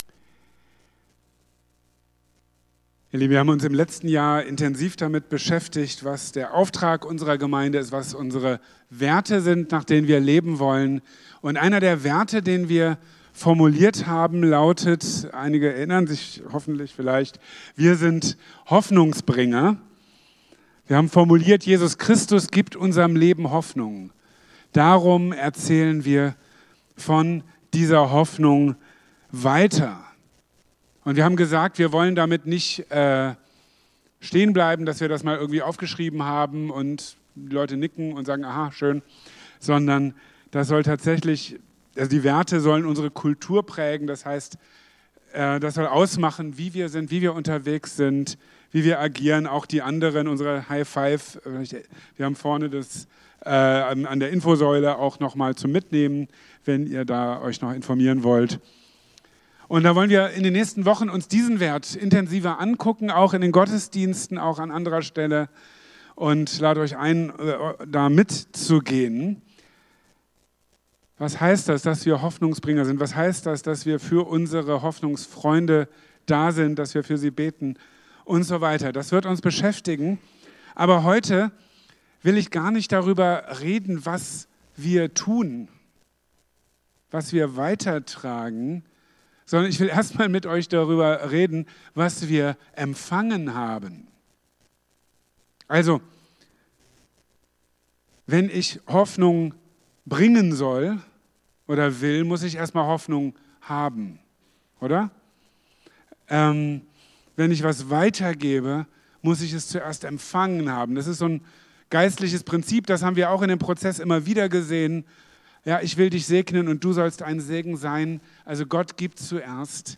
Aber bevor wir darüber sprechen, wie wir anderen Menschen Hoffnung bringen, müssen wir uns fragen: Haben wir überhaupt Hoffnung? In seiner Predigt vom 16.